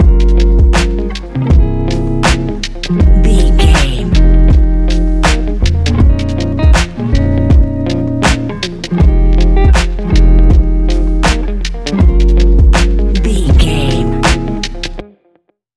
Ionian/Major
F♯
laid back
Lounge
sparse
new age
chilled electronica
ambient
atmospheric